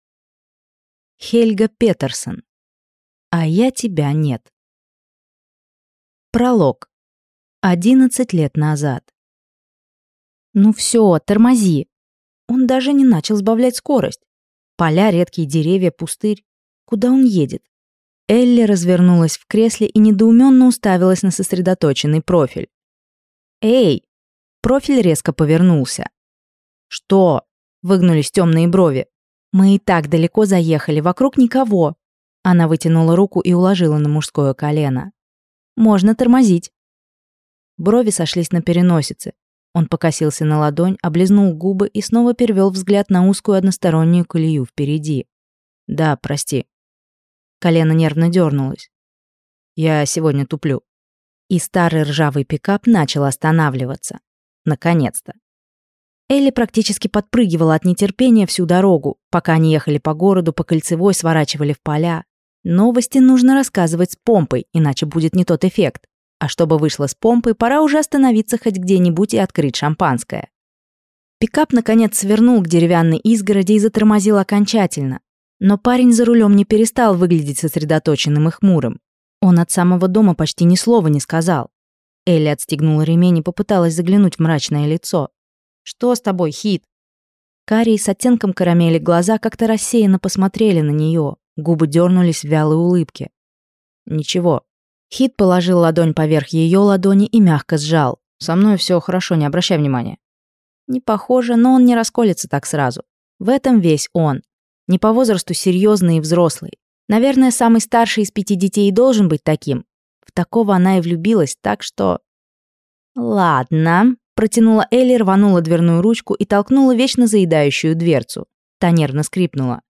Аудиокнига А я тебя нет | Библиотека аудиокниг